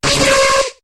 Cri de Migalos dans Pokémon HOME.